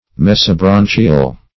Search Result for " mesobranchial" : The Collaborative International Dictionary of English v.0.48: Mesobranchial \Mes`o*bran"chi*al\, a. [Meso- + branchial.] (Zool.) Of or pertaining to a region of the carapace of a crab covering the middle branchial region.